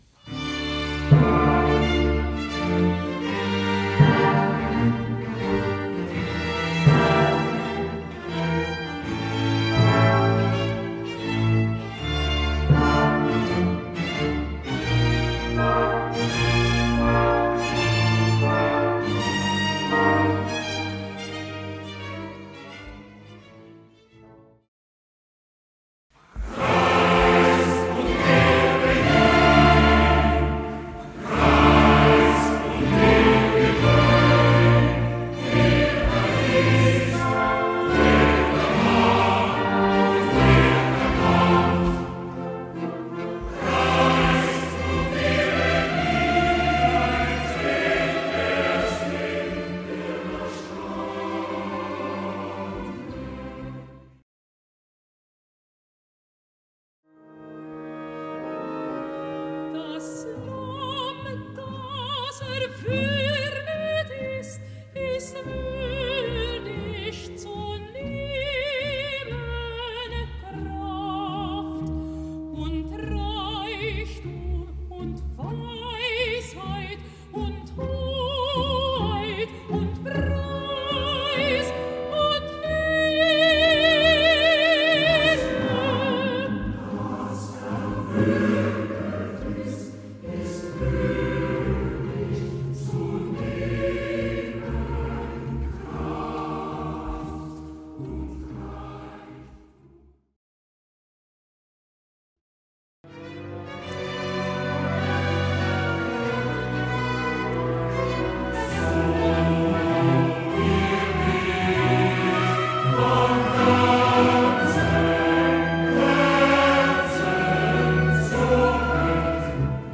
Das Oratorium „Die letzten Dinge“ (1825 bis 1826 entstanden) war eines der erfolgreichsten Werke Spohrs, nicht zuletzt wegen der besonders gelungenen Chorpartien.
Die Komposition besticht durch meisterhaft eingesetzte Instrumentationstechnik, überlegen eingesetzte Chromatik, großangelegte Solorezitative und eingängige Chorpartien voll inniger Empfindsamkeit und aufwühlender Dramatik.